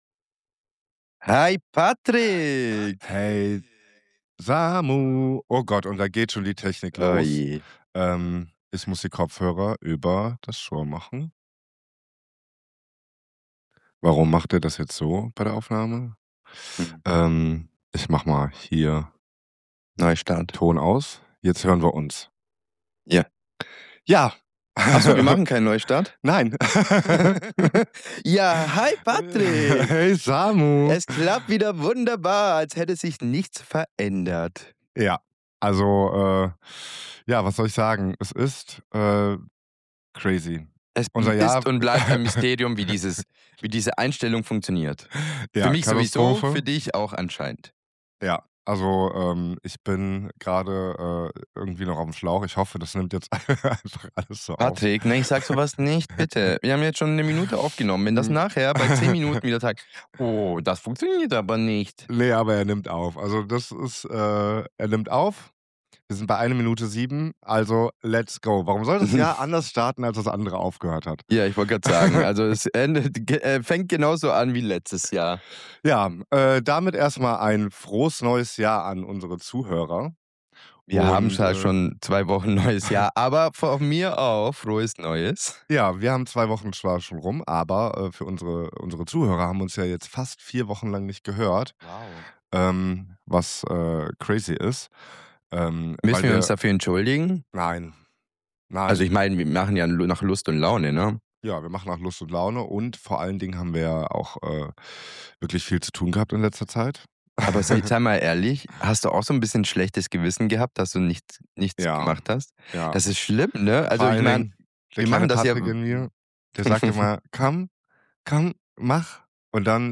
In dieser Folge stolpern wir uns gemeinsam ins Jahr 2026: mit Technikproblemen, Echo im Ohr, Silvester-Trauma in der Großstadt und der großen Frage, warum Raketen erlaubt sind, aber Papierstrohhalme nicht...
Beziehungs-Zickerei live & ungeschnitten